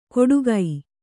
♪ koḍugai